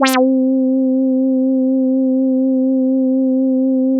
Whacka Fuzz C3.wav